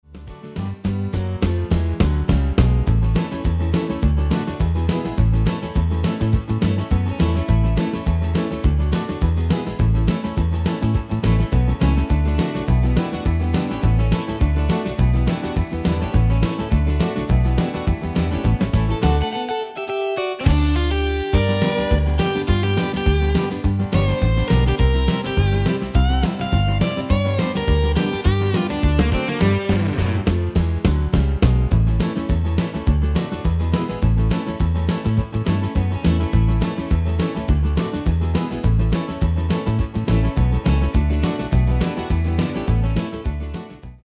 Demo/Koop midifile
Genre: Country & Western
Toonsoort: G/A
- Vocal harmony tracks